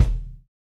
Roland.Juno.D _ Limited Edition _ Brush Kit _ BD.wav